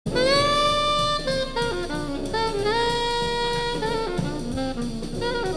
Massey Hall, Toronto, Canada